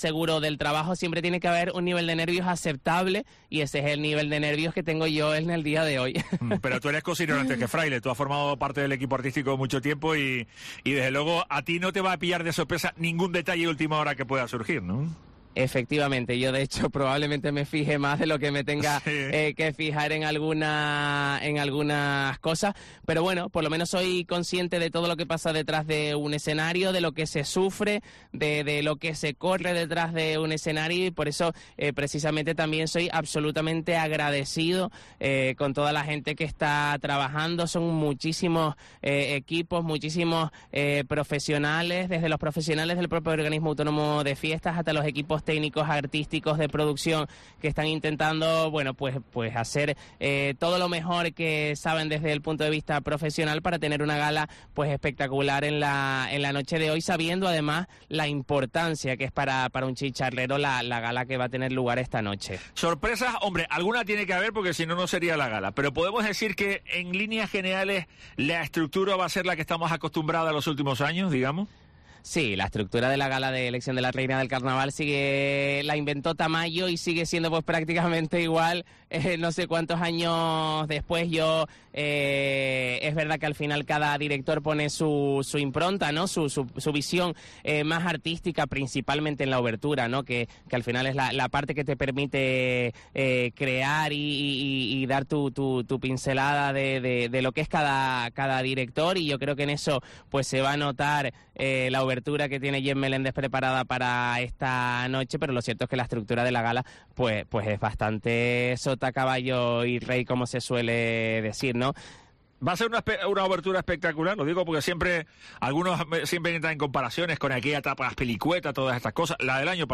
Hoy el concejal de Fiestas, Javier Caraballero, ha despejado la incógnita, de forma tajante, en Herrera en COPE Tenerife.